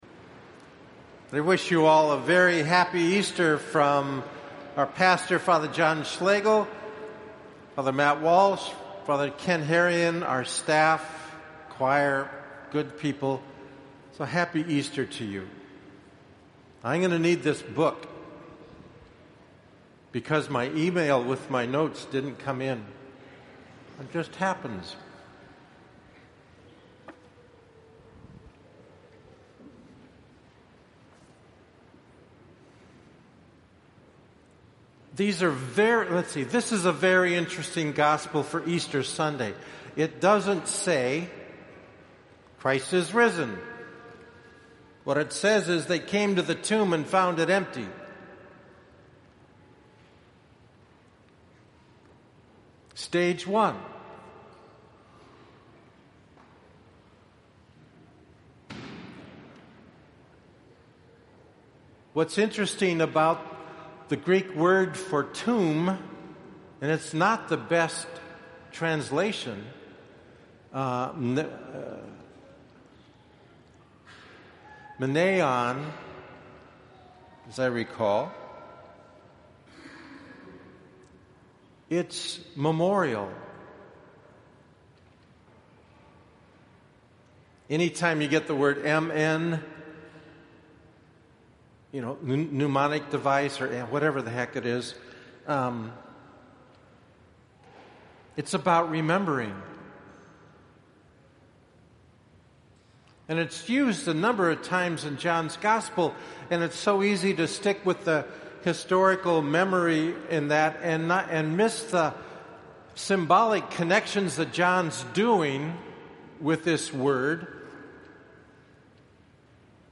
Homily
Easter Sunday 2015 (9:30 a.m. Mass)